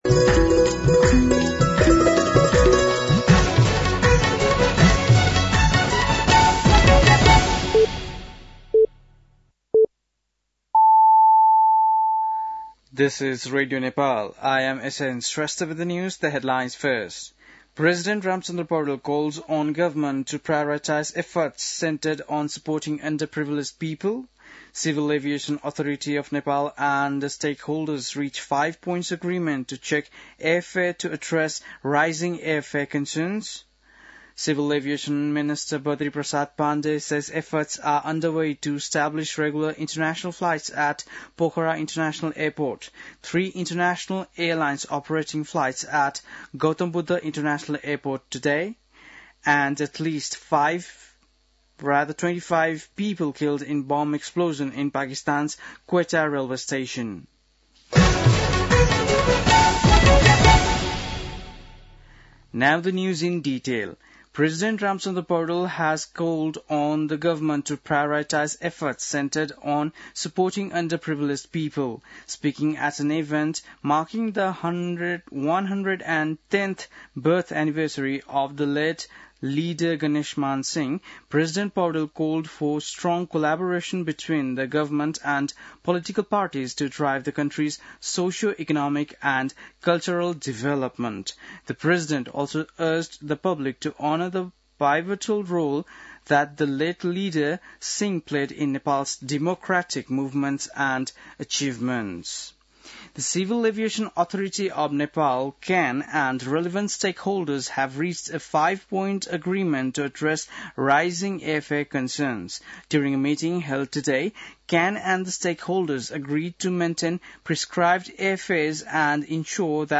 An online outlet of Nepal's national radio broadcaster
बेलुकी ८ बजेको अङ्ग्रेजी समाचार : २५ कार्तिक , २०८१